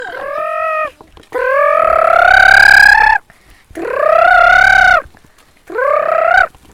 동물소리흉내.mp3